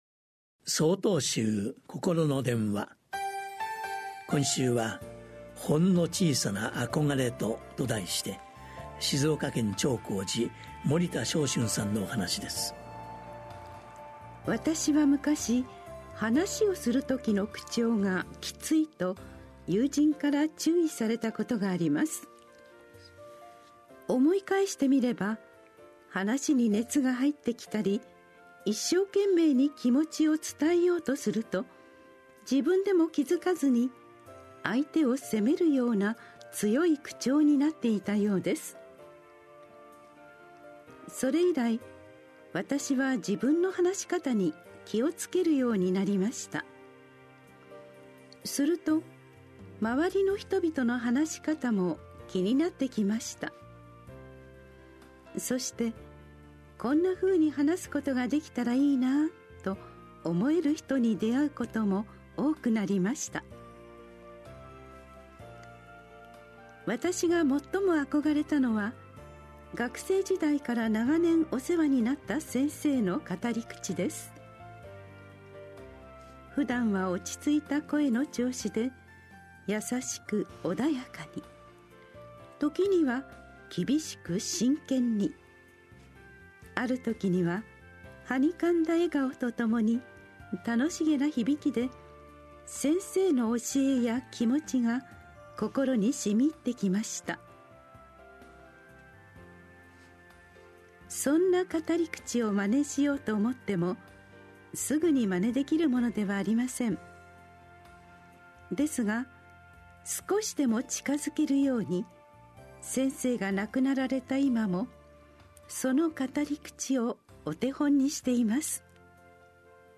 曹洞宗では毎週、わかりやすい仏教のお話（法話）を、電話と音声やポッドキャストにて配信しています。